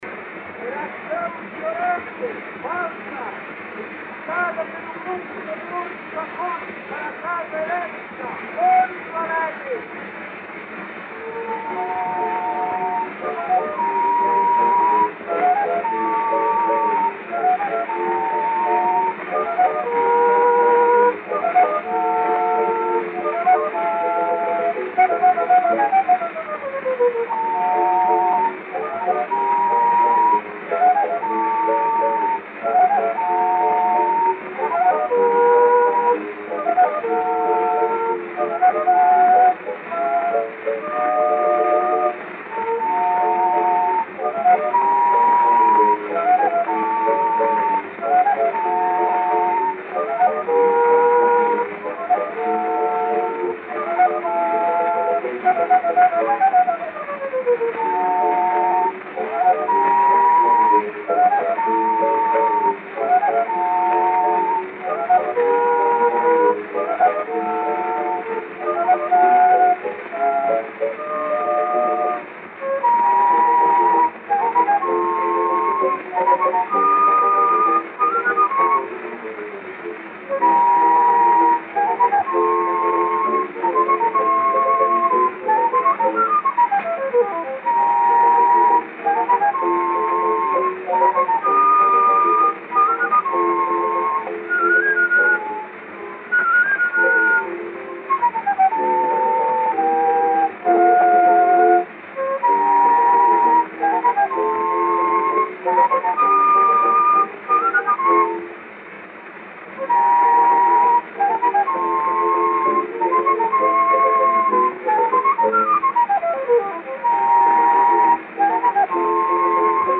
Disco de 78 rotações, também chamado "78 rpm", gravado em apenas um lado e com rótulo "tricolor".
Gênero: Valsa.